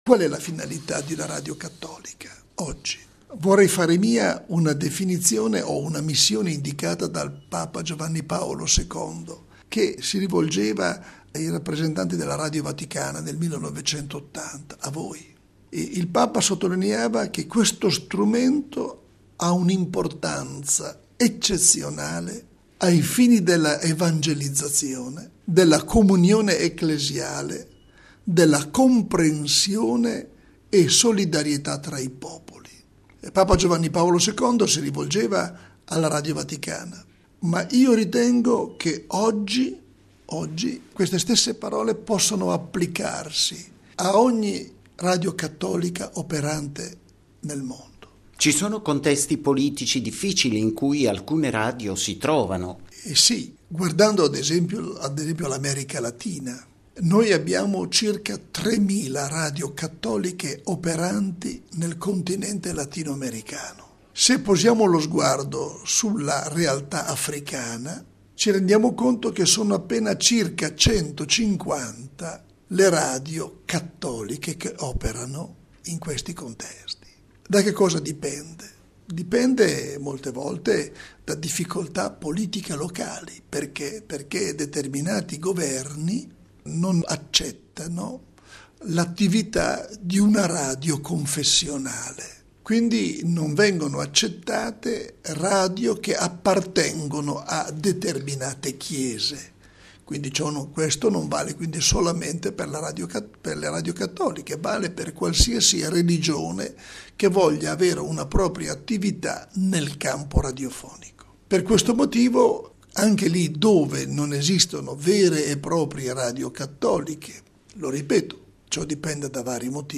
Inizia domani a Roma il Congresso per le Radio Cattoliche: intervista con l’arcivescovo Celli